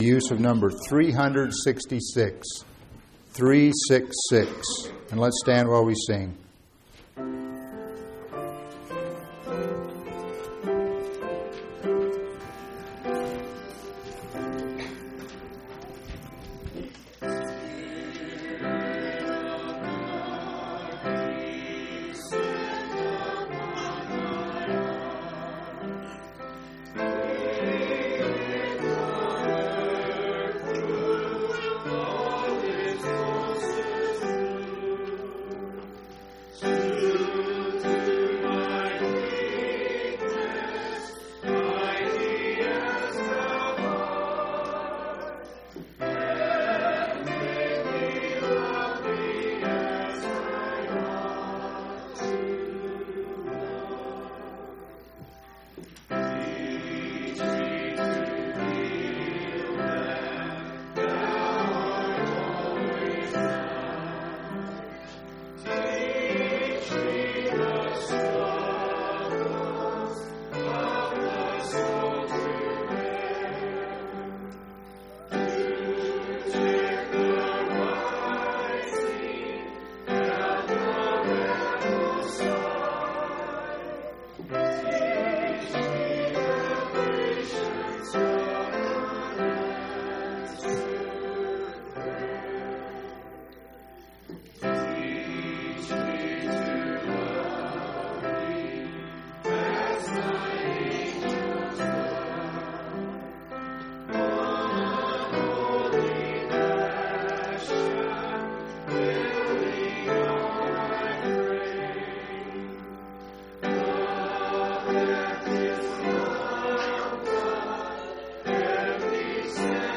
3/9/2003 Location: Phoenix Local Event